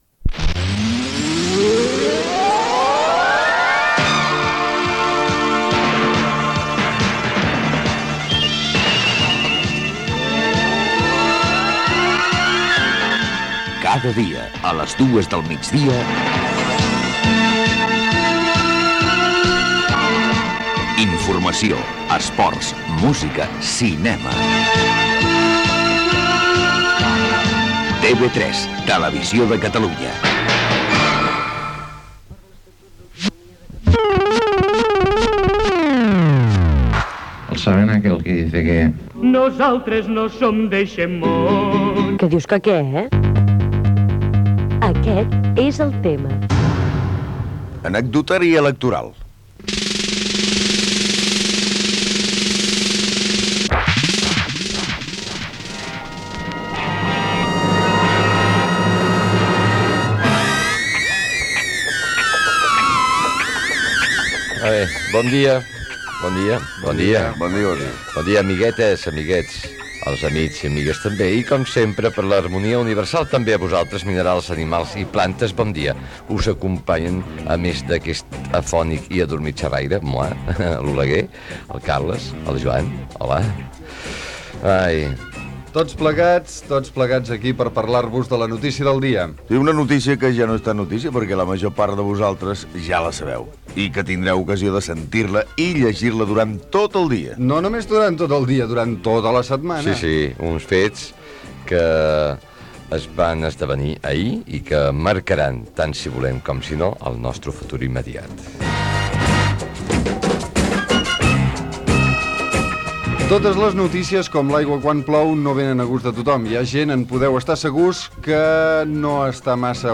0e3f0ddfc0ee0e6534bf6286fa1dacefb2a84cbc.mp3 Títol Catalunya Ràdio Emissora Catalunya Ràdio Cadena Catalunya Ràdio Titularitat Pública nacional Nom programa Aquest és el tema Descripció Promoció de TV3. Espai dedicat a l'"Anecdotari electoral" Gènere radiofònic Divulgació
Banda FM